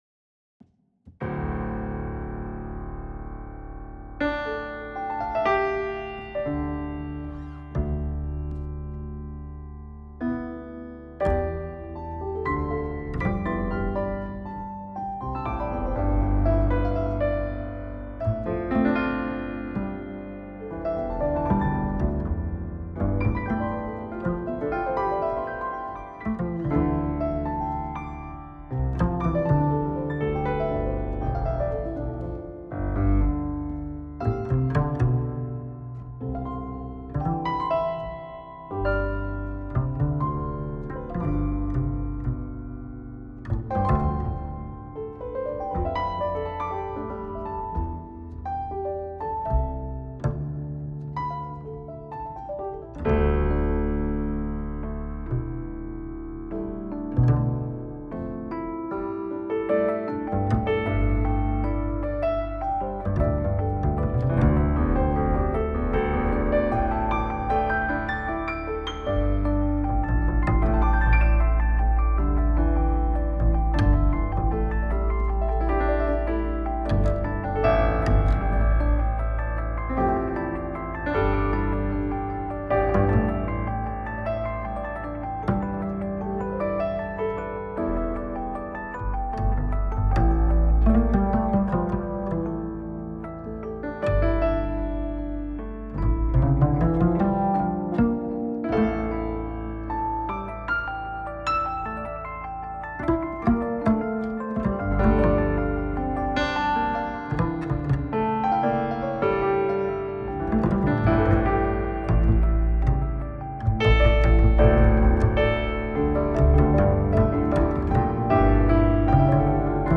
Piano, Fender Rhodes et Contrebasse